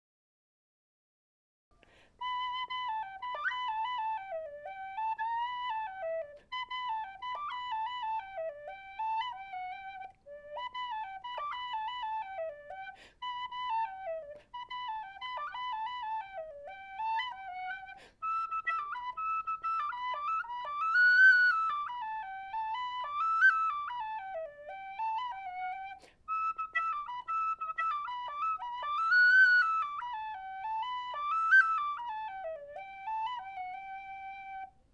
Number: #34 Key: High D Date completed: March 2025 Type: A telescoping brass high D model with brass head and black plastic fipple plug.
Volume: Moderate. The first octave could be considered quieter, but the second octave will tip into moderate volume.